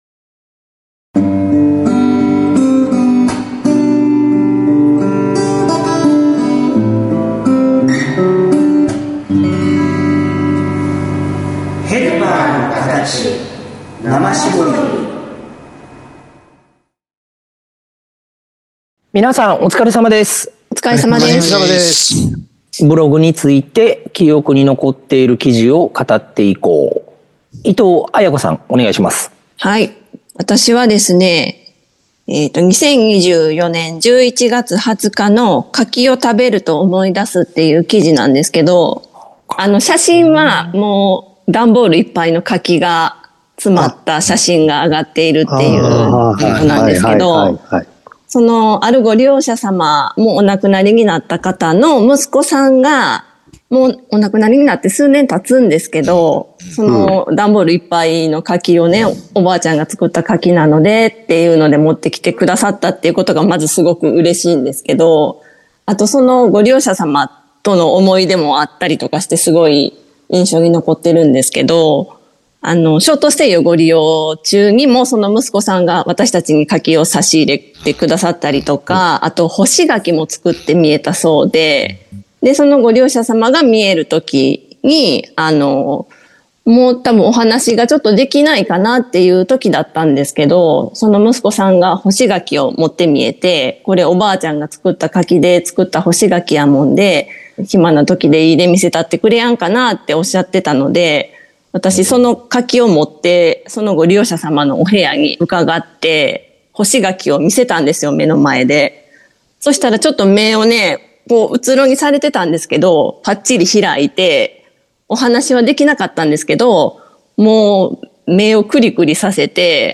＜今週のテーマ＞ 先週に引き続きまして、 弊社HPの毎日更新ブログに ついて、振り返りの談論を 配信致します！